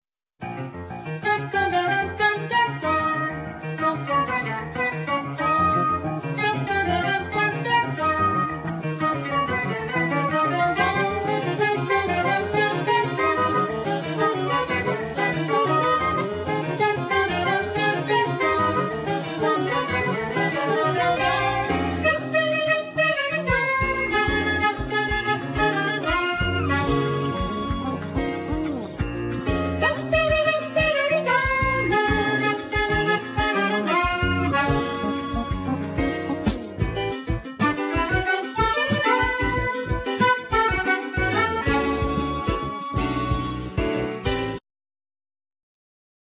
Piano
Keyboards
Soprano Saxophne
Vocal
Bass
Drums
Percussion